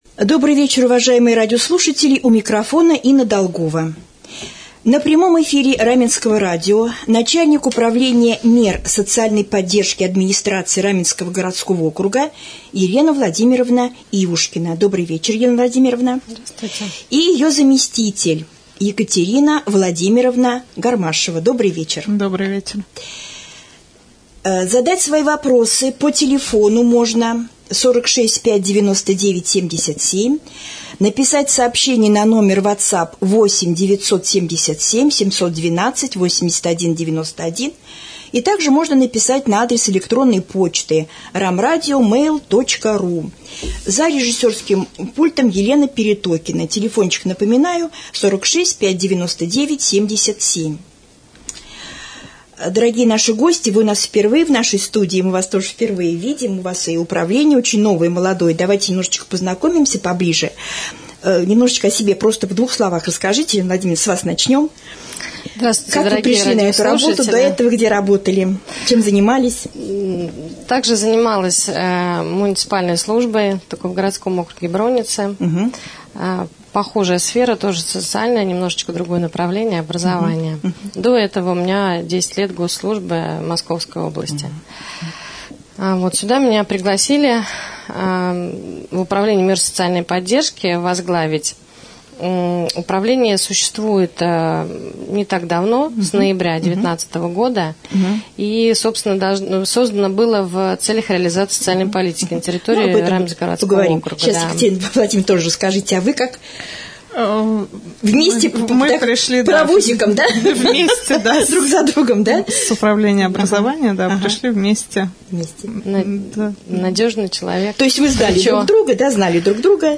prjamoj-jefir.mp3